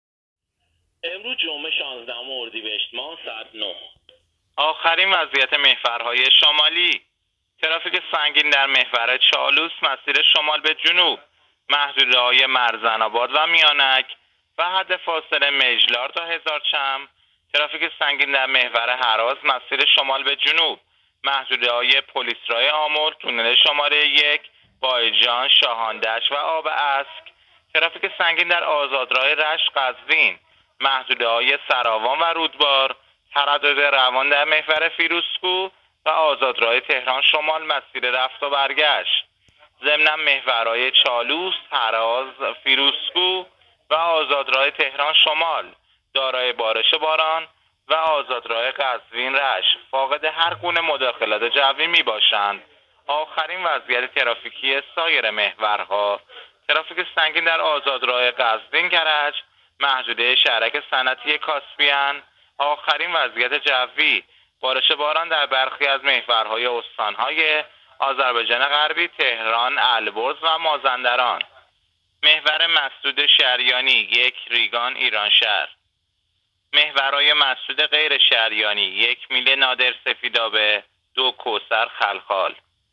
گزارش رادیو اینترنتی از آخرین وضعیت ترافیکی جاده‌ها تا ساعت ۹ شانزدهم اردیبهشت؛